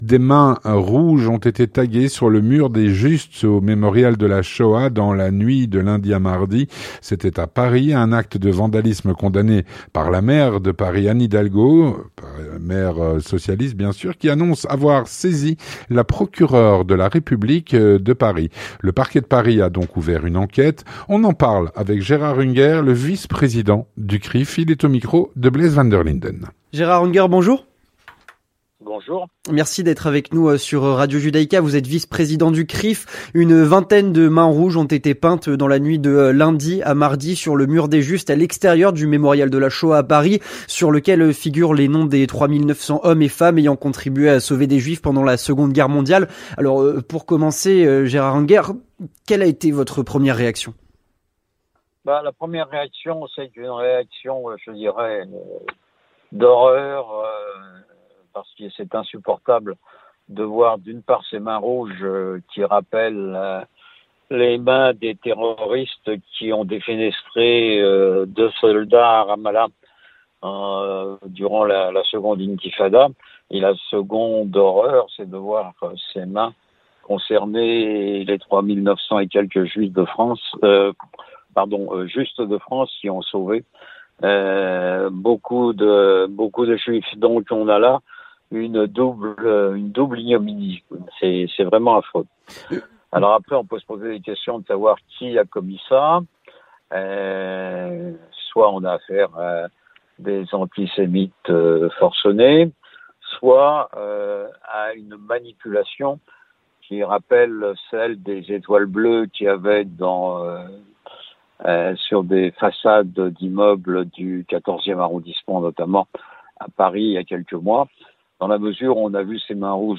L'entretien du 18H - Des mains rouges ont été taguées sur le Mur des Justes du Mémorial de la Shoah dans la nuit de lundi à mardi à Paris.